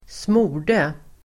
Uttal: [²sm'o:r_de]